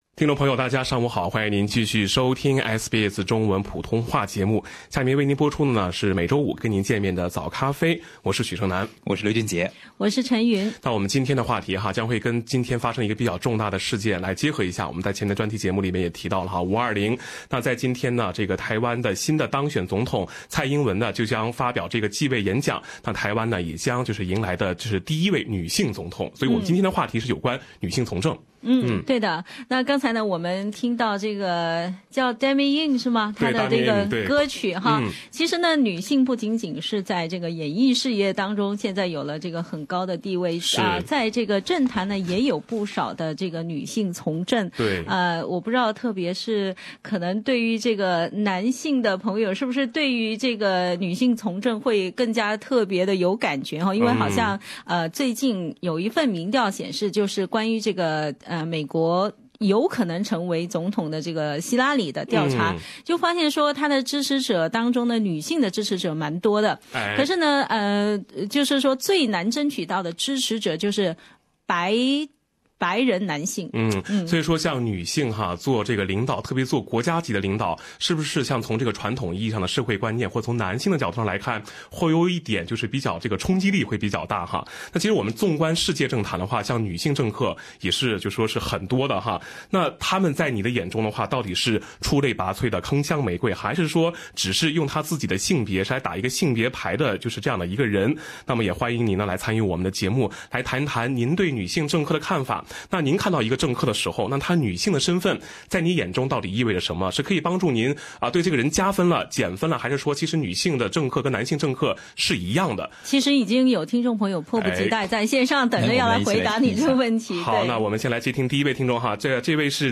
《早咖啡》節目，主持人和您聊聊女性從政：對於政客，女性的身份在您眼中是加分、減分，還是會一視同仁呢？